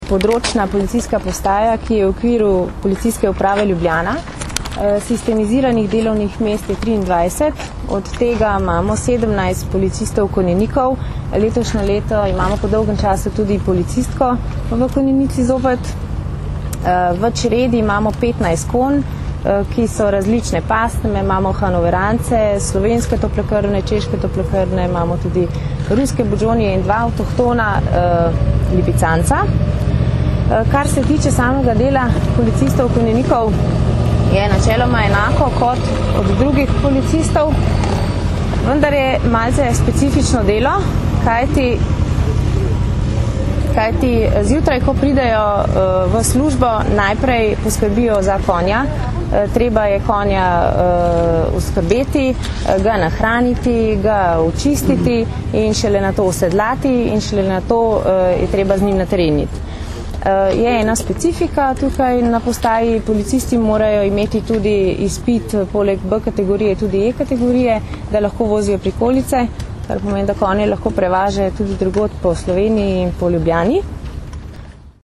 V ljubljanskih Stožicah smo novinarjem danes, 24. avgusta 2009, predstavili Postajo konjeniške policije in specifično delo policistov konjenikov, ki službene konje uporabljajo za patruljiranje, iskanje oseb, pri hujših kršitvah javnega reda in miru ter za druge naloge policije.
Zvočni posnetek izjave